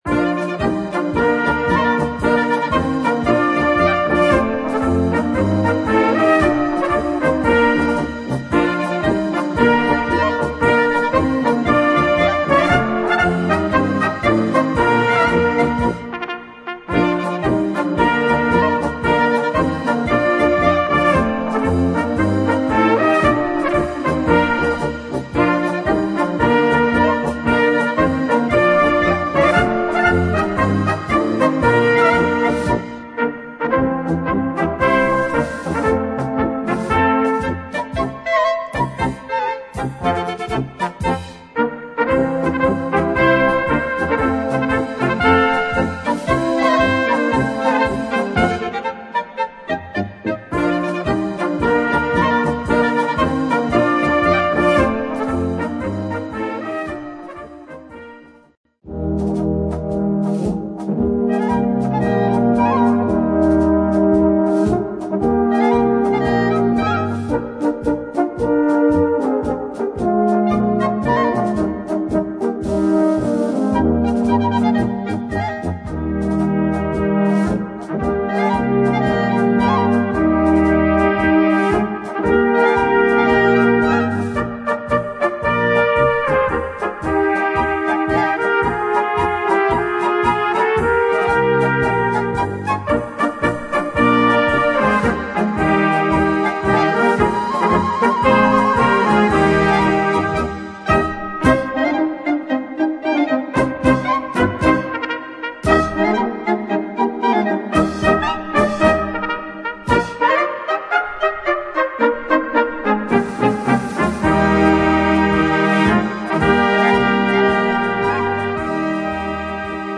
Gattung: Polka
19 x 14 cm Besetzung: Blasorchester Zu hören auf